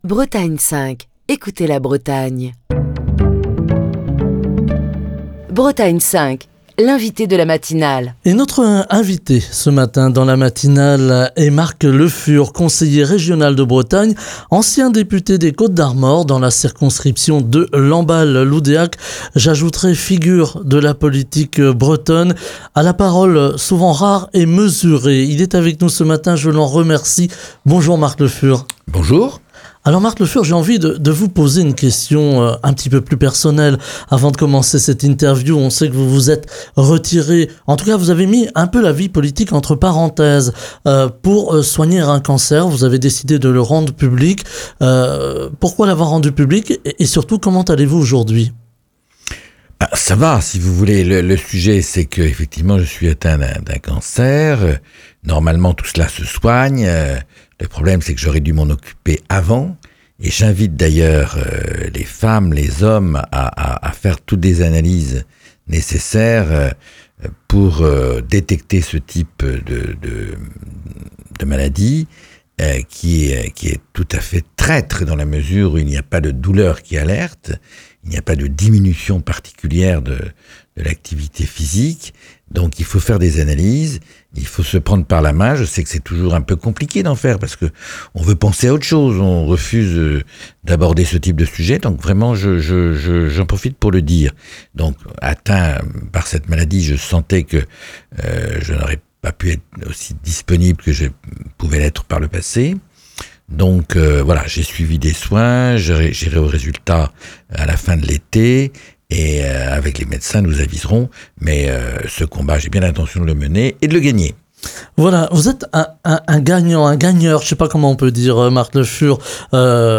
Marc Le Fur, conseiller régional de Bretagne, ancien député des Côtes-d'Armor, dans la circonscription de Lamballe-Loudéac, est l'invité de la matinale de Bretagne 5.